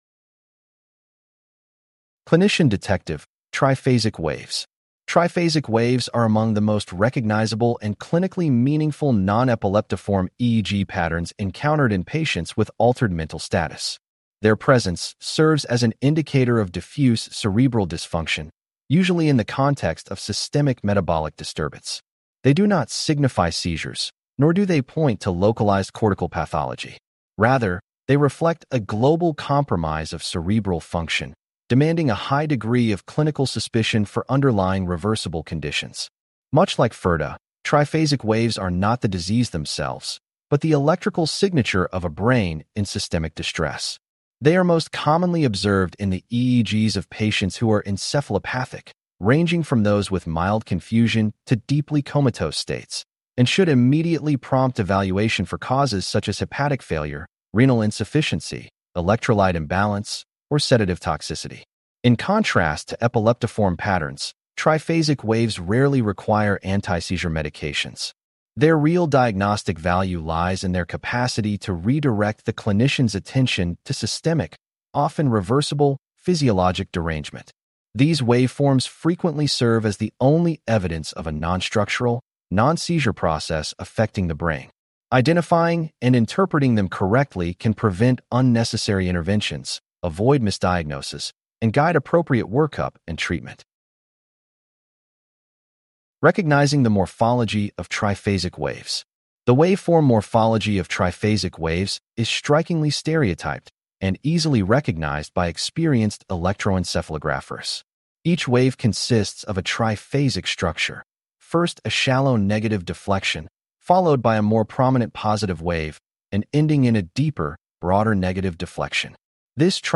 CLICK TO HEAR THIS POST NARRATED Their presence serves as an indicator of diffuse cerebral dysfunction, usually in the context of systemic metabolic disturbance.